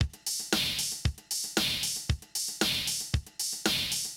MOO Beat - Mix 4.wav